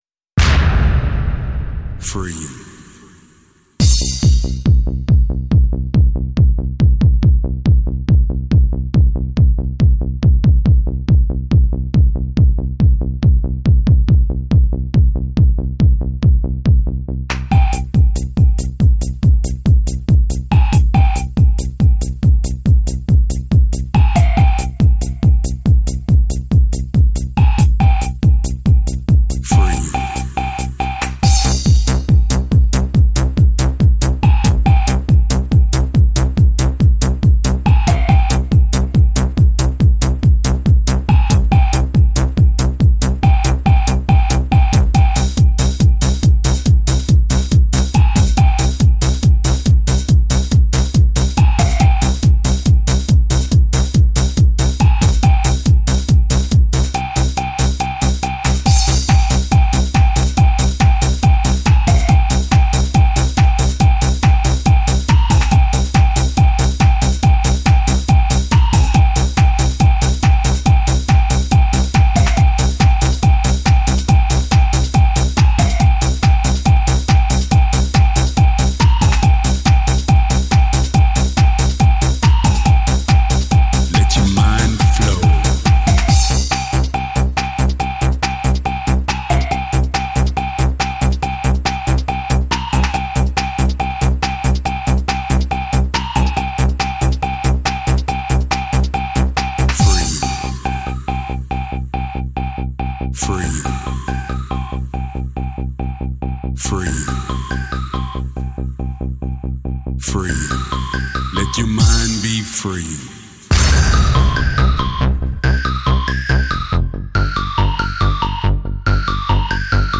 Жанр: Eurodance